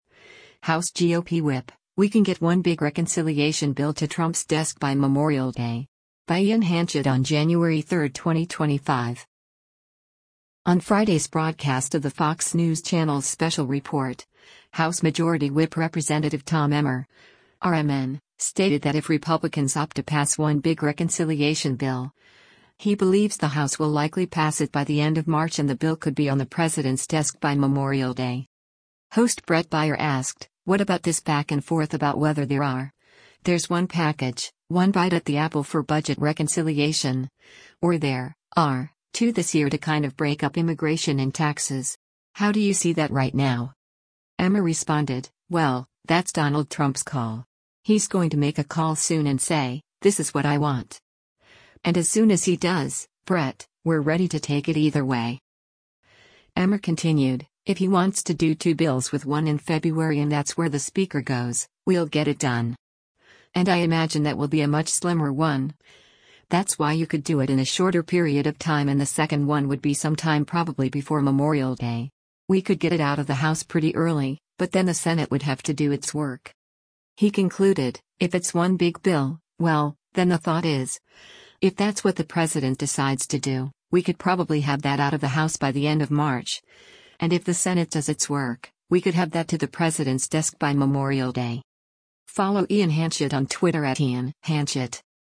On Friday’s broadcast of the Fox News Channel’s “Special Report,” House Majority Whip Rep. Tom Emmer (R-MN) stated that if Republicans opt to pass one big reconciliation bill, he believes the House will likely pass it by the end of March and the bill could be on the president’s desk by Memorial Day.
Host Bret Baier asked, “What about this back-and-forth about whether there are — there’s one package, one bite at the apple for budget reconciliation, or there [are] two this year to kind of break up immigration and taxes? How do you see that right now?”